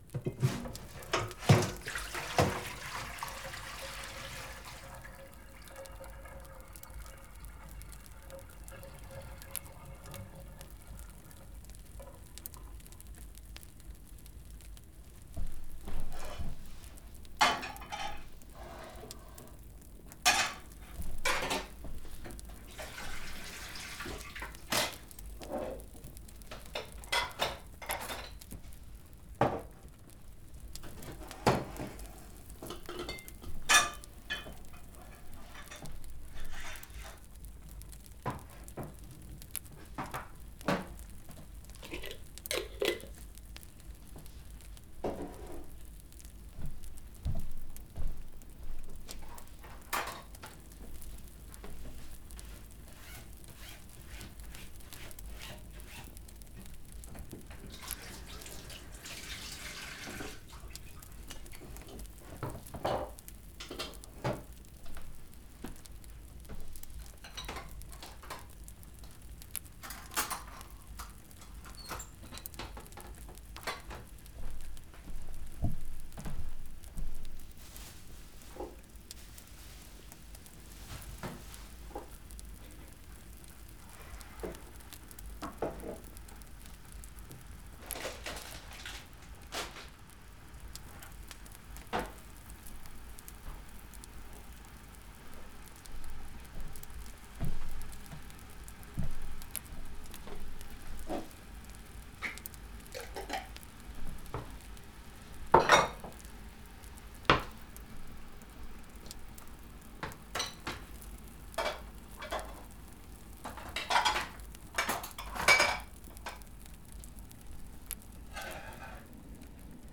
Some Tingly comfort for you!